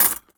R - Foley 223.wav